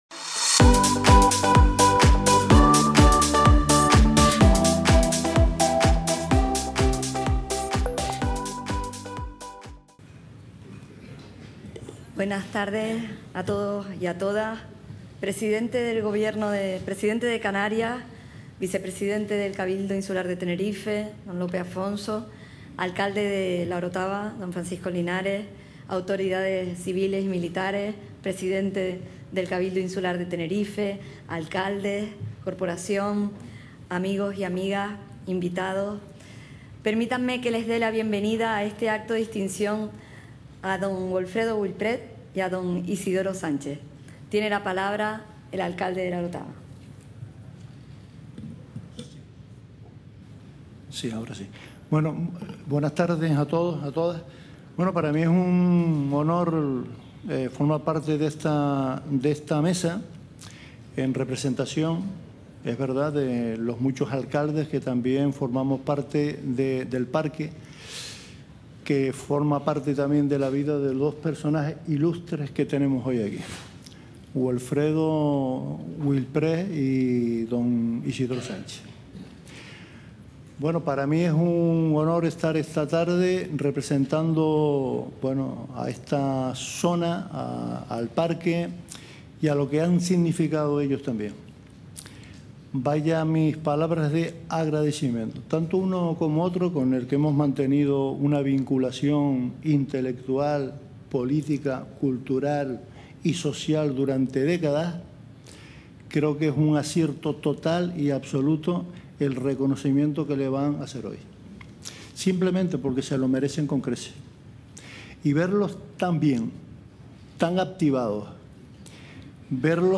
Acto de homenaje